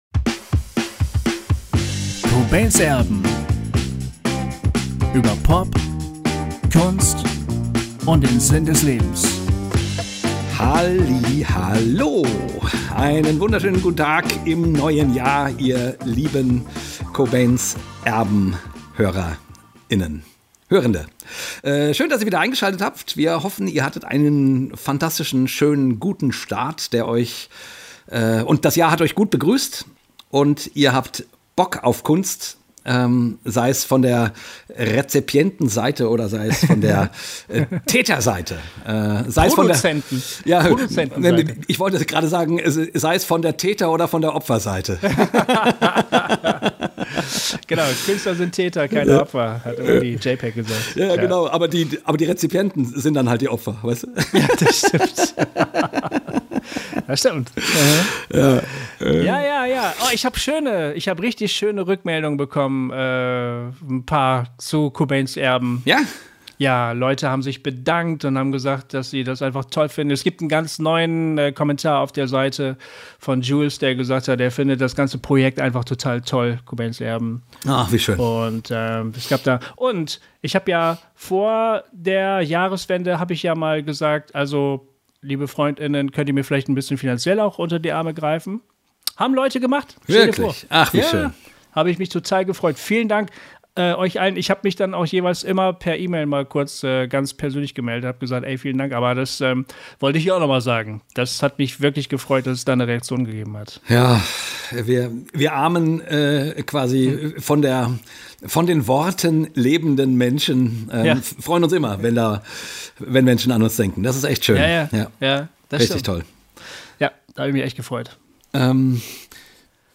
Wir haben uns die sehenswerte Netflix-Doku über ihn extra noch einmal angeschaut und reden drüber: Wie geht man mit diesen Gefühlen um? Wodurch entstehen sie? Und gibt es ein Heilmittel, einen Ausweg aus der Misere? Viel Spaß bei diesem lockeren Gespräch zweier Betroffener!